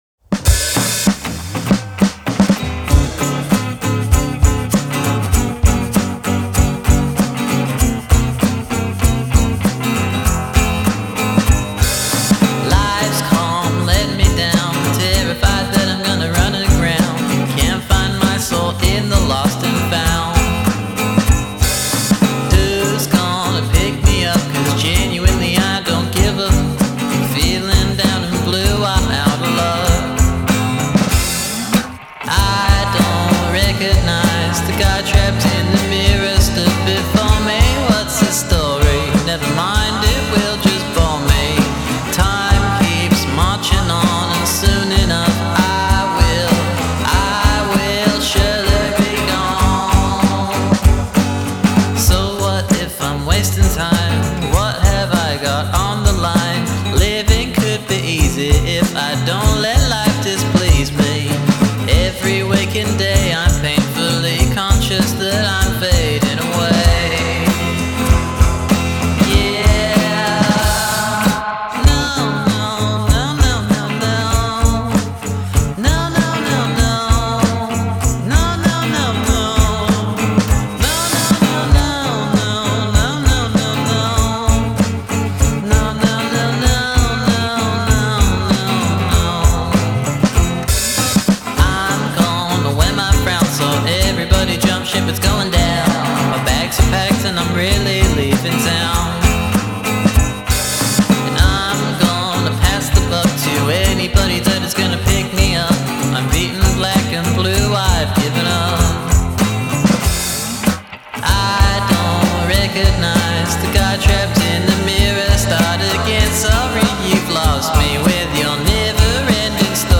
using only analogue recording techniques
The track’s purposefully jovial delivery